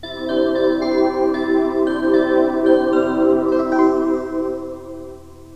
Senyal desconnexió publicitària duranel Nadal